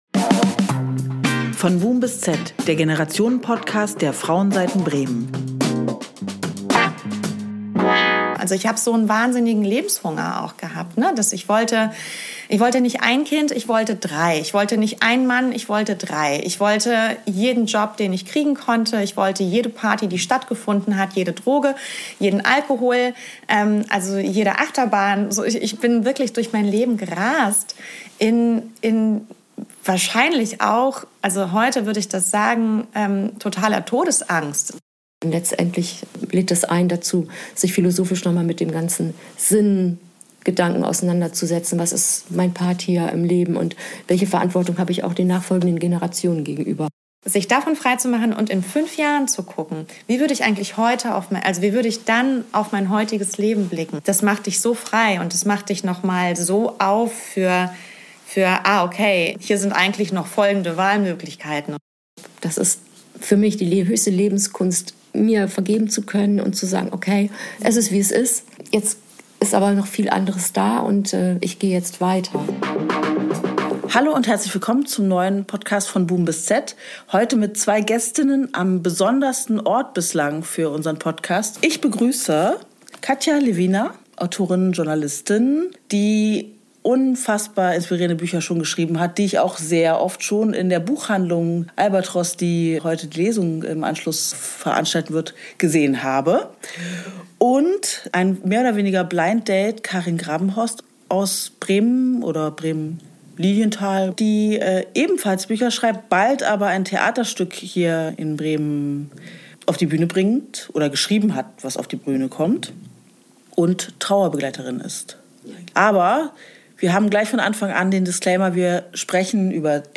In der neuen Folge unseres Generationenpodcasts „Von Boom bis Z“ treffen sich drei Generationen im Kinosaal des Institut Français, um über ein großes Thema zu sprechen: Endlichkeit.